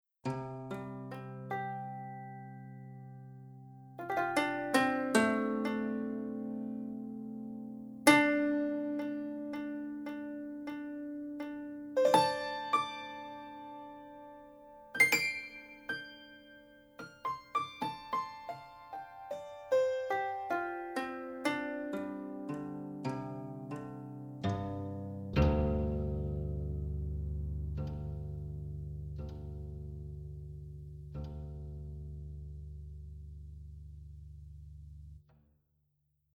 Mini Pianos
Das Instrument ist mit zwei mischbaren Mikrofonpositionen, Close und Room, versehen. Color Shift, Transient und Release Noise lassen Klangfarben von weich und sanft bis zu brillant und direkt zu.